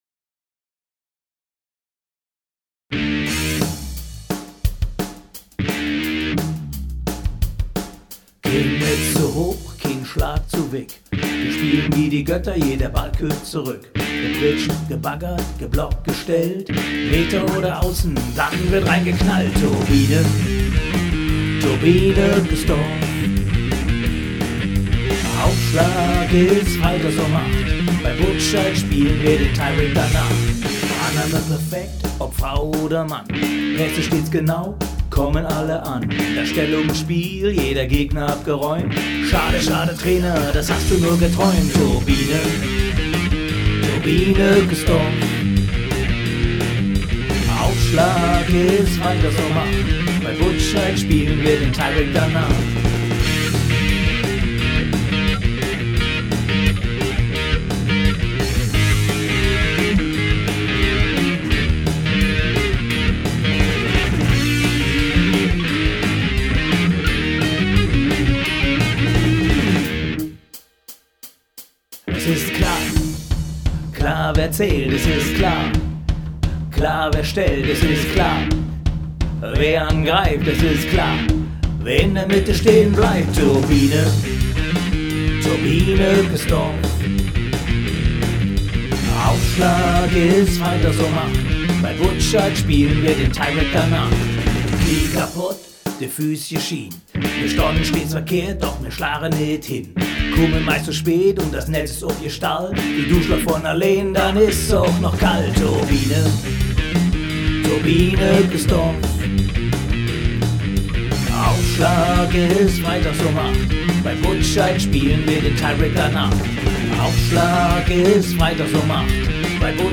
Rock
Volleyball-Rock aus Ückesdorf – in der Tres Hombres Version!